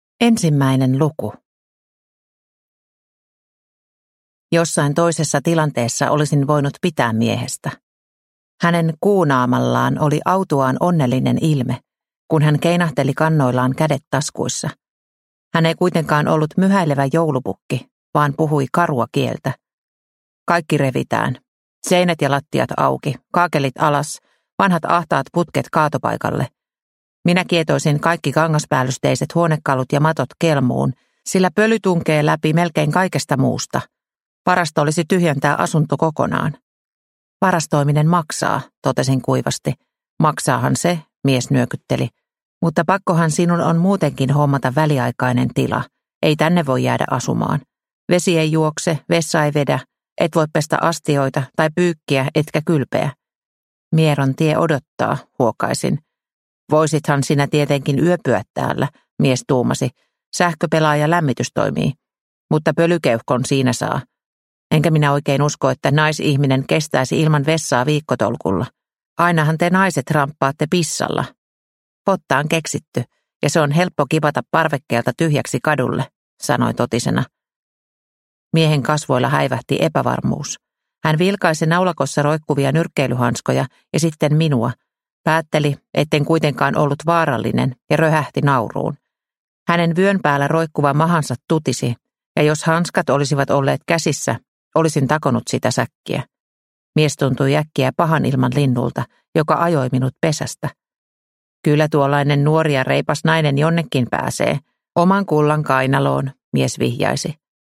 Kolme miestä netissä – Ljudbok – Laddas ner